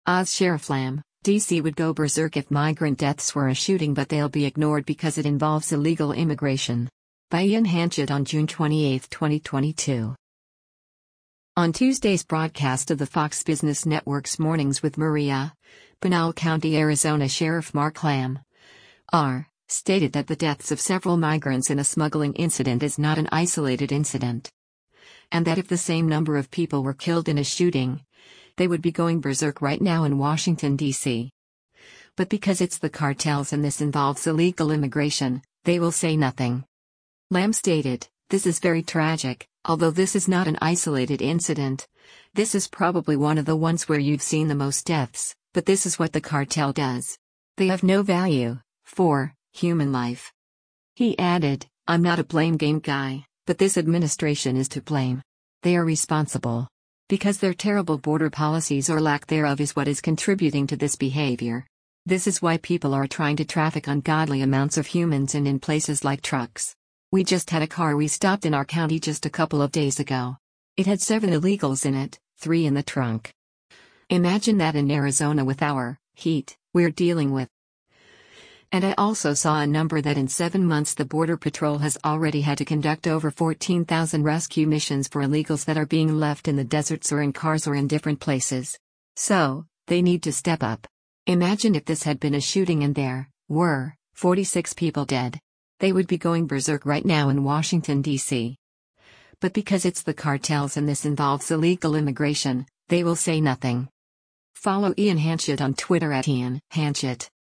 On Tuesday’s broadcast of the Fox Business Network’s “Mornings with Maria,” Pinal County, AZ Sheriff Mark Lamb (R) stated that the deaths of several migrants in a smuggling incident “is not an isolated incident.”